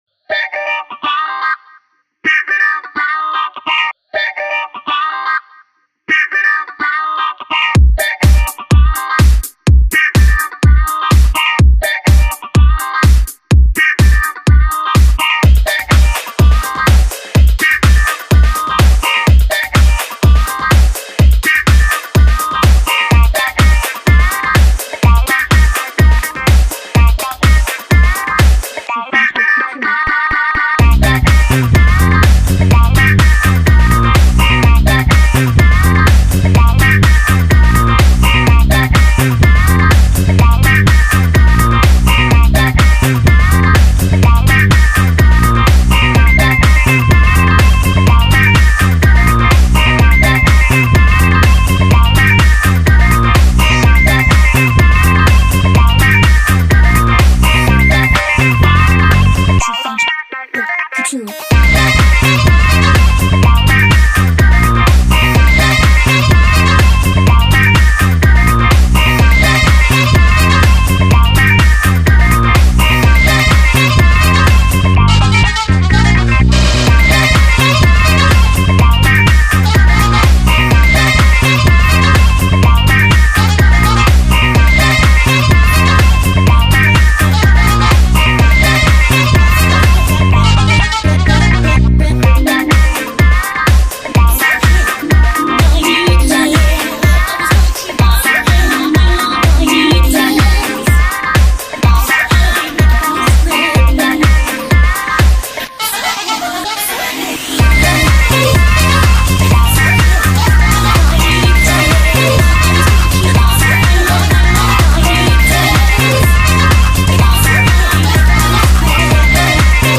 I thought some disco house would be appropriate.